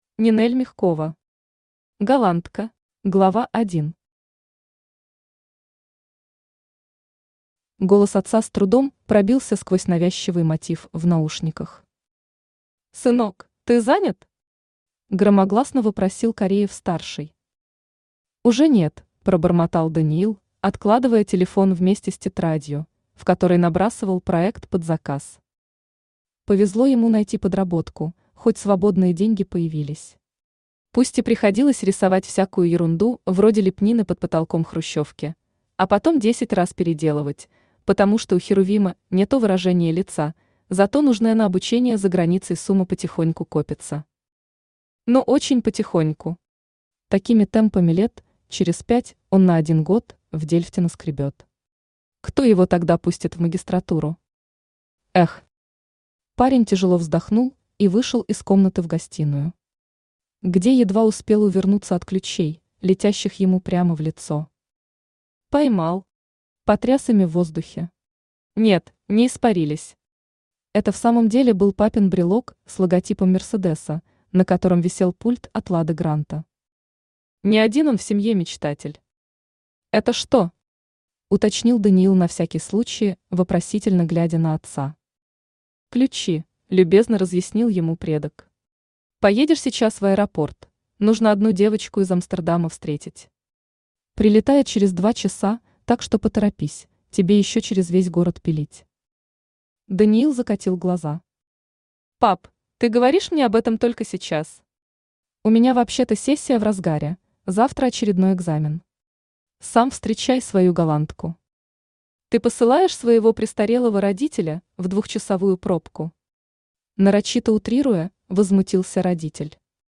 Аудиокнига Голландка